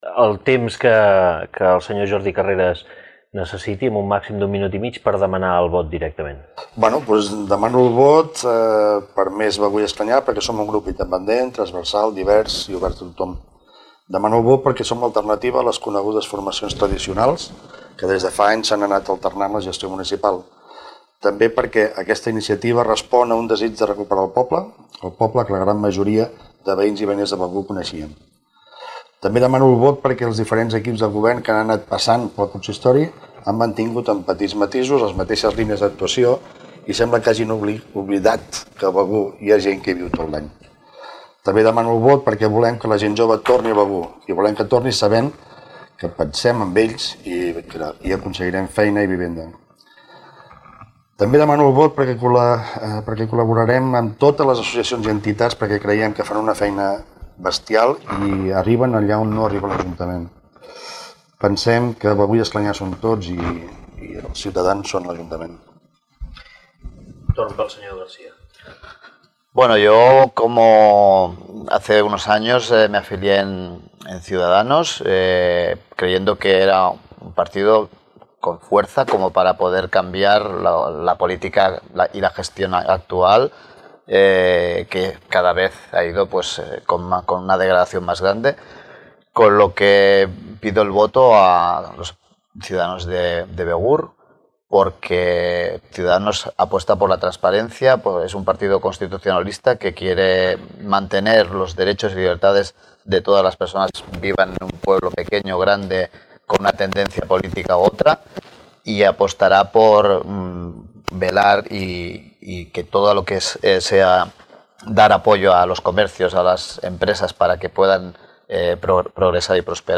Escolta aquí el minut final per a demanar el vot de cada candidat al debat electoral Begur 2019.